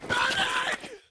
stunned.ogg